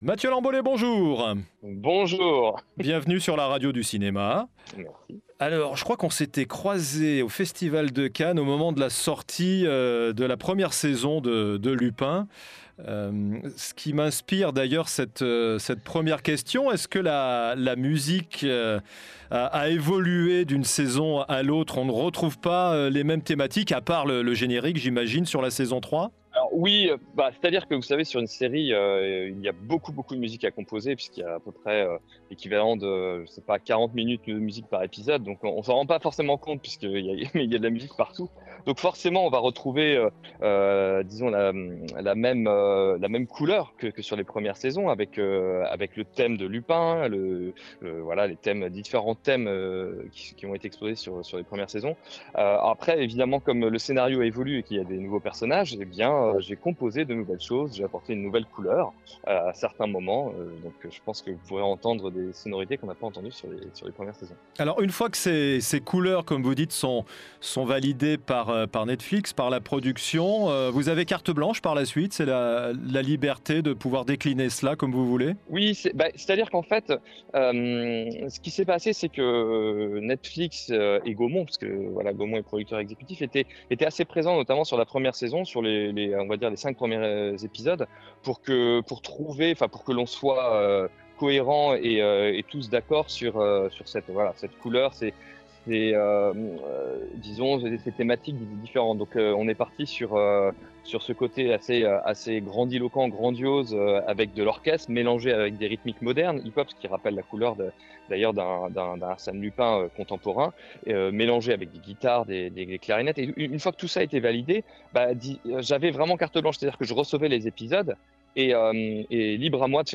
Les Secrets Musicaux de "Lupin" : Une Conversation avec Mathieu Lamboley